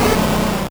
Cri de Snubbull dans Pokémon Or et Argent.